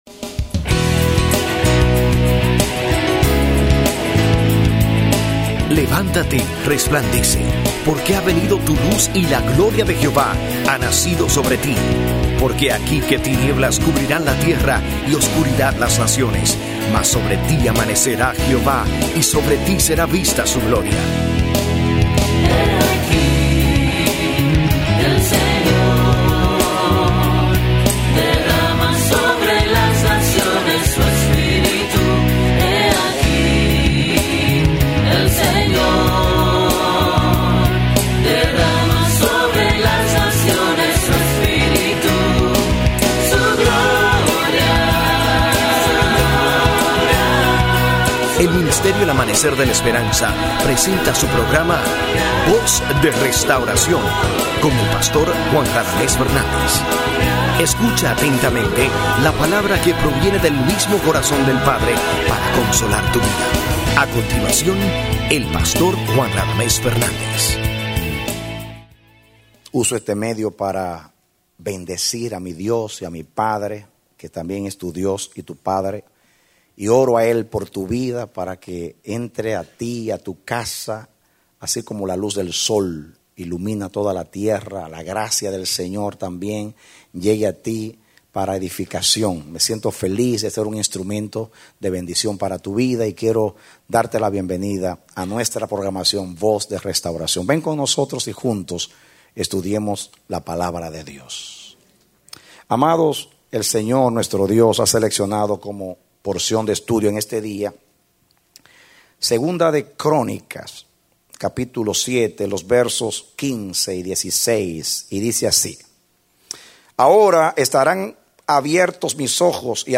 Mensaje: “Puso Su Corazón”